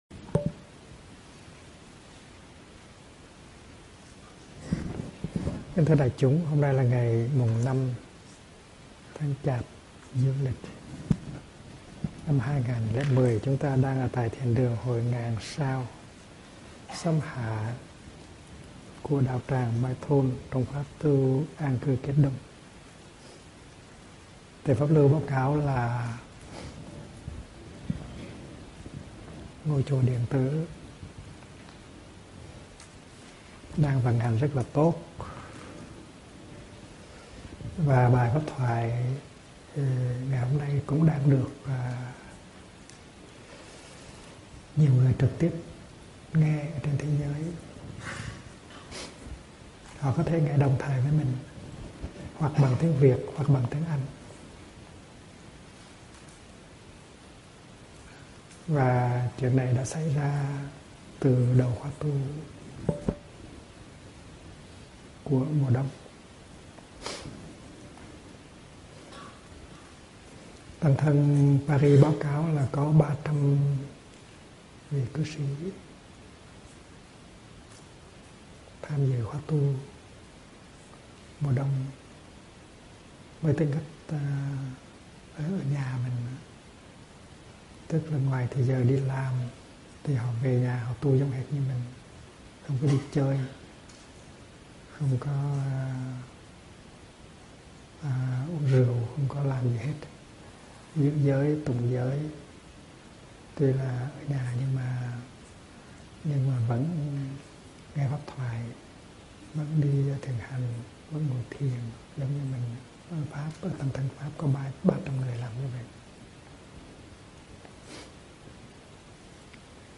Mời quý phật tử nghe mp3 thuyết pháp Chế tác hạnh phúc do HT. Thích Nhất Hạnh giảng